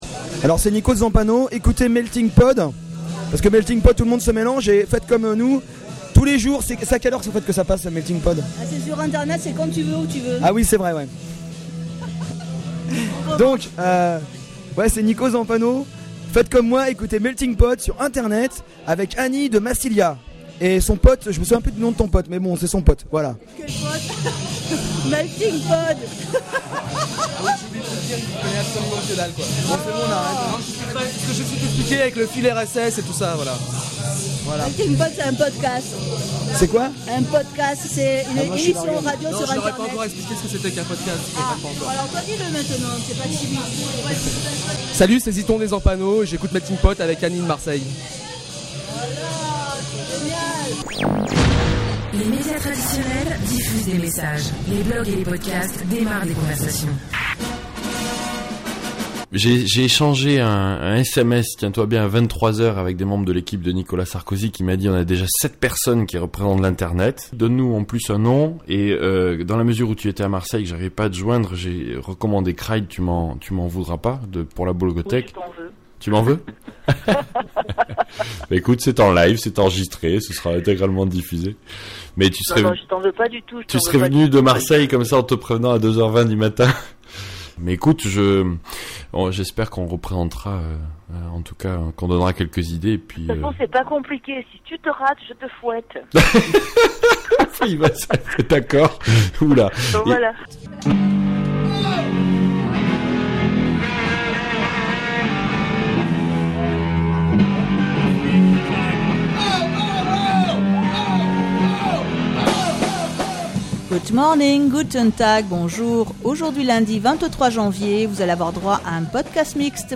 La loi DADVSI vue par deux podcasteurs français plutôt impliqués dans l’élaboration de ce nouveau texte